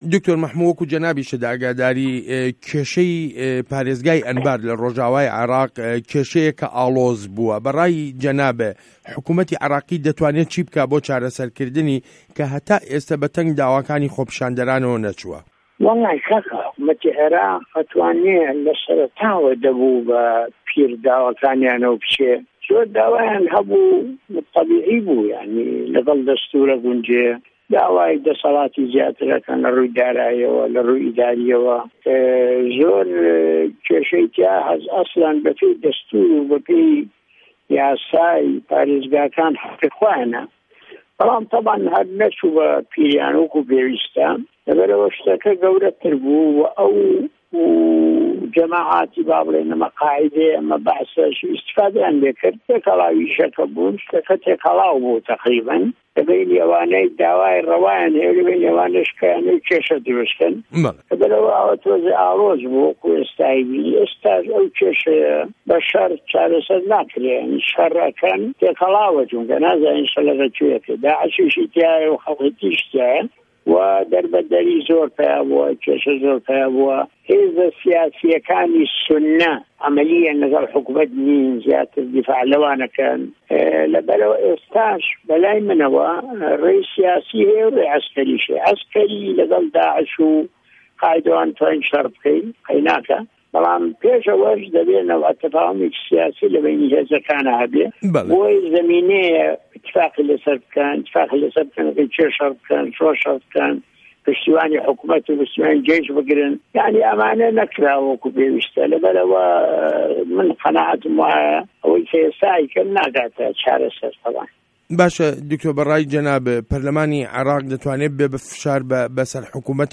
وتووێژ له‌گه‌ڵ دکتۆر مه‌حمود عوسمان